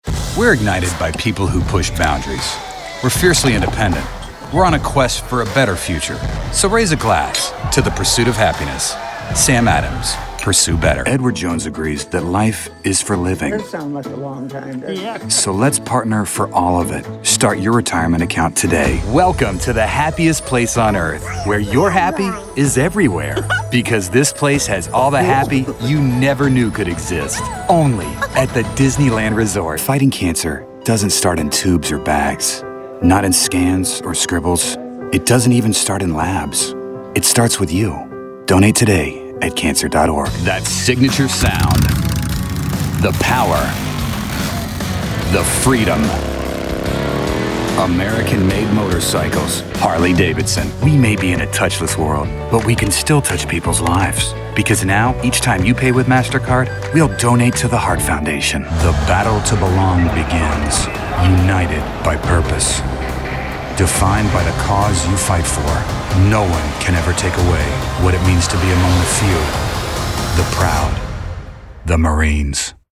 Bright, Upbeat, Youthful.
Commercial